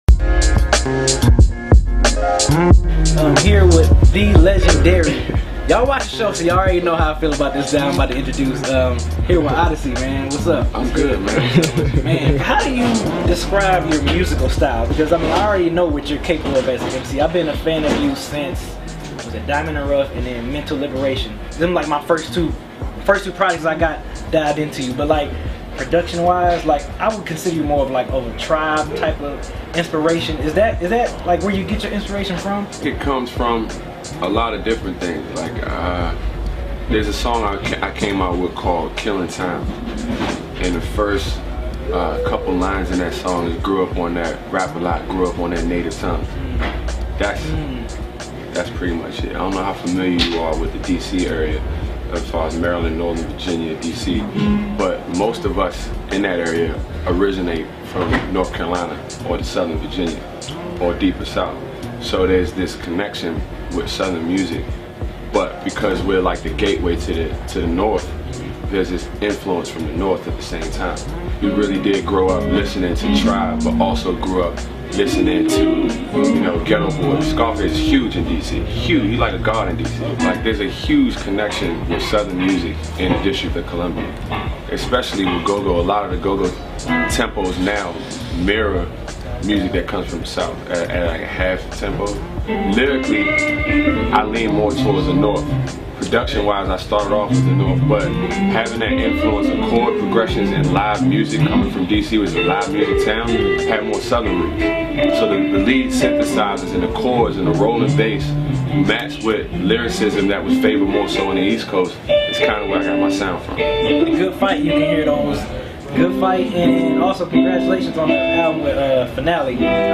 Oddisee Interview (Extended Version) Patrons Only